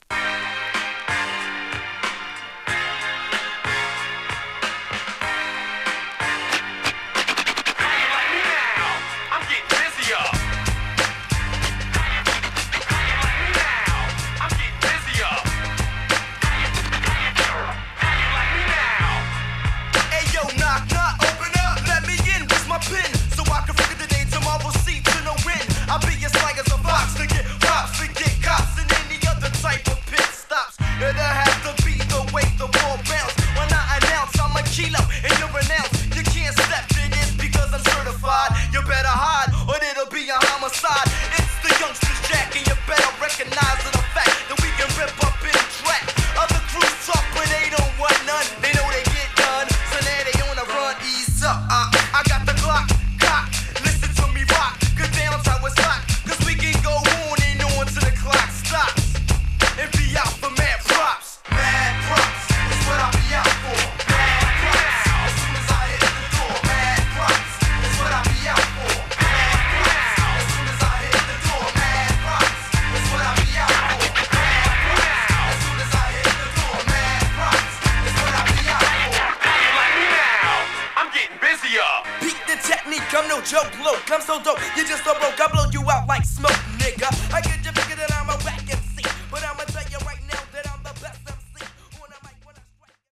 2. > HIPHOP